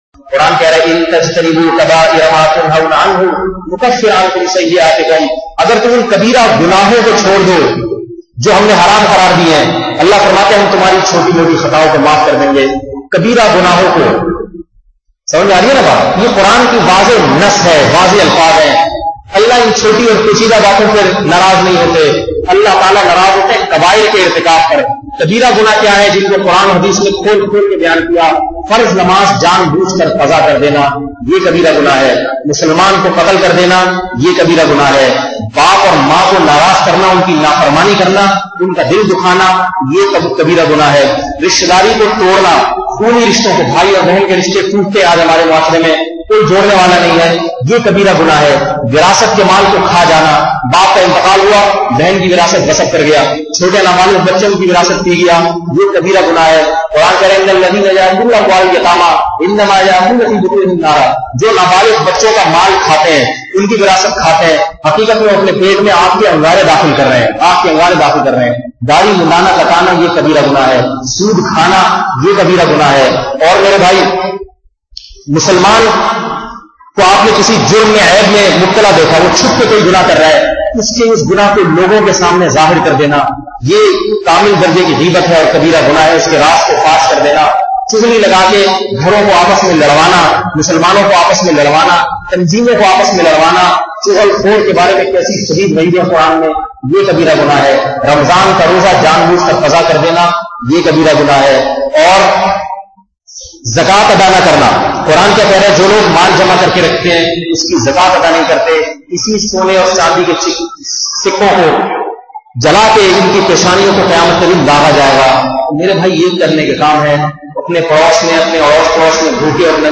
Kabeera Gunnah konse hai? ~ Mufti Tariq Masood Sahab DB Audio Bayanat